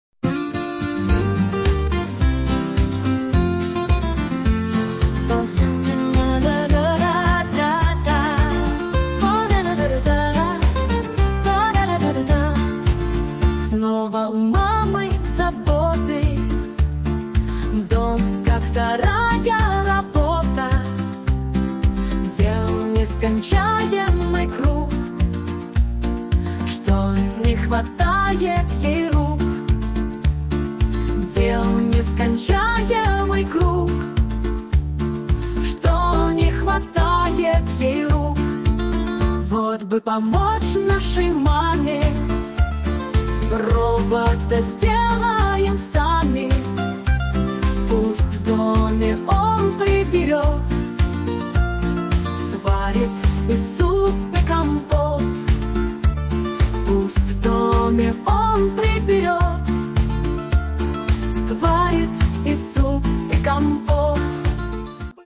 Песня детская про робота для мамы
Фрагмент примера исполнения женским голосом: